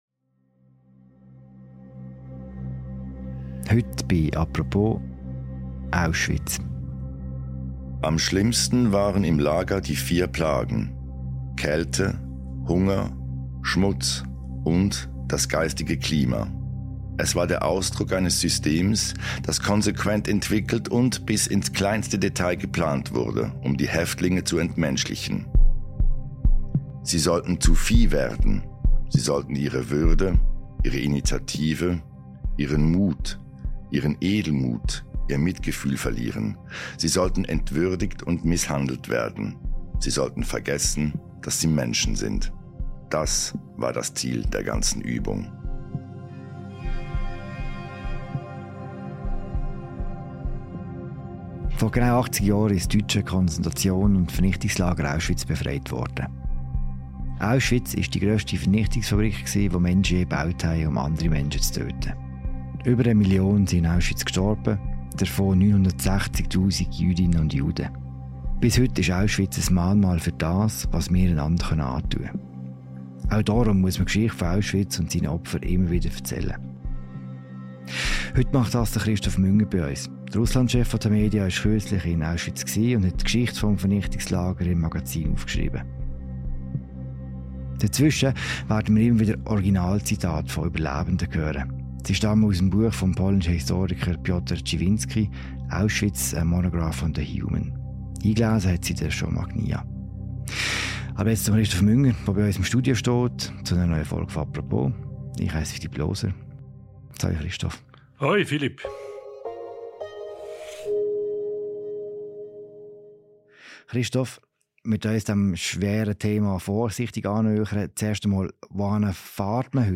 In der Folge sind Originalzitate vo nÜberlebenden zu hören.